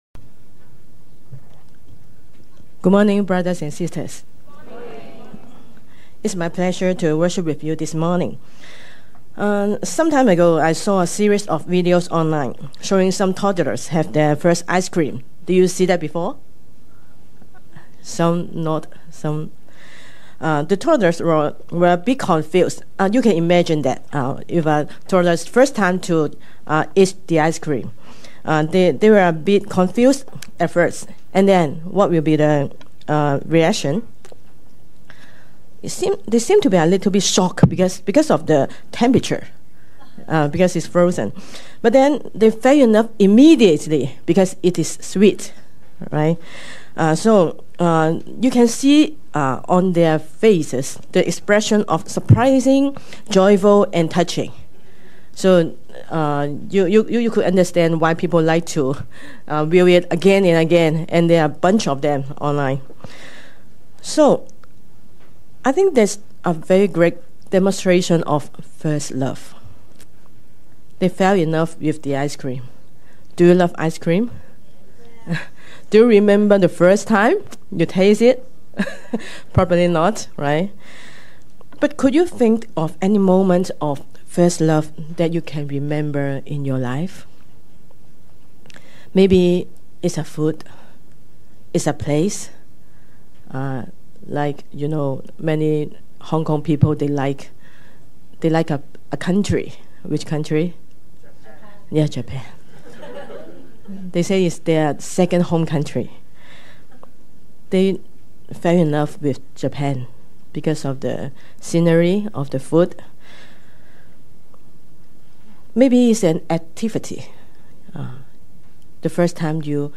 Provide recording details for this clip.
English Worship (LCK) - First Love